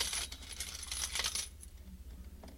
pbs - pluggz coins [ Sfx ].wav